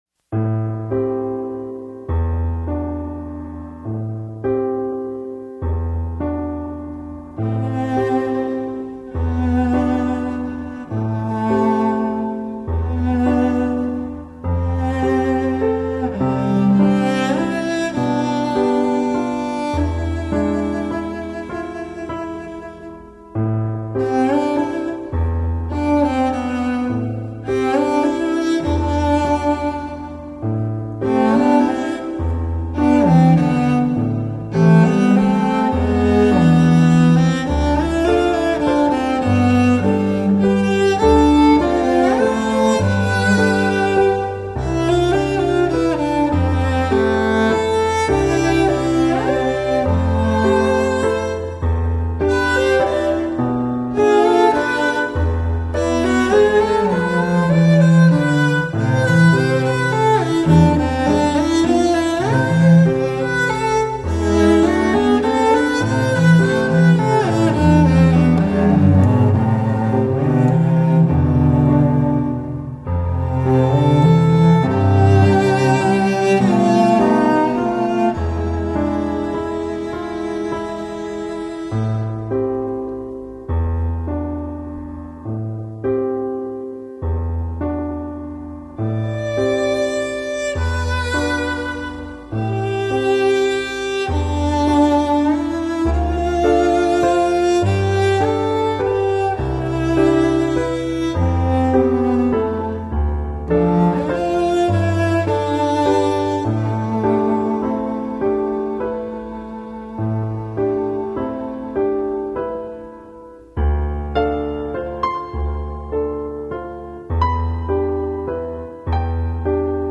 配乐系列
大提琴
钢琴